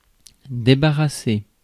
Ääntäminen
US : IPA : [ˈrɪd]